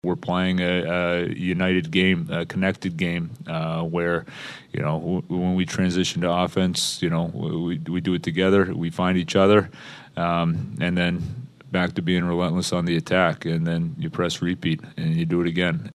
Coach Dan Muse says the Penguins are winning because they are playing a consistent all-around game.